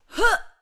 SFX_Battle_Vesna_Attack_01.wav